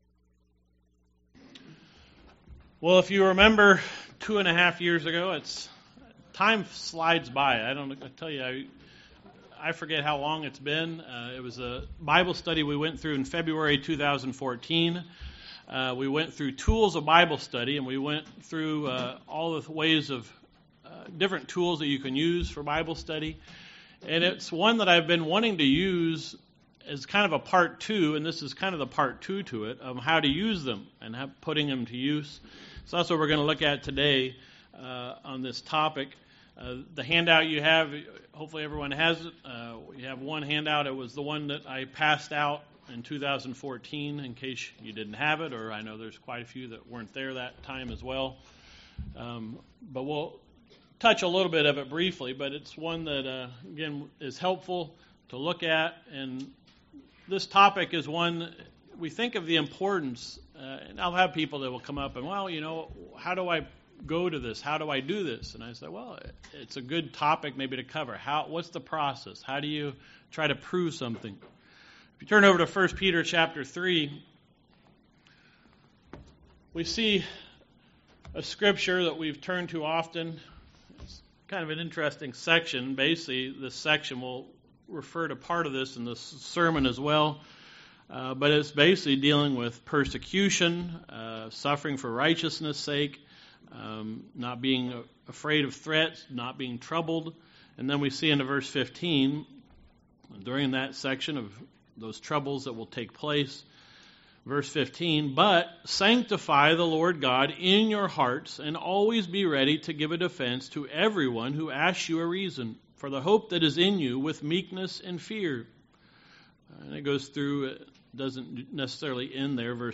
Given in Northwest Indiana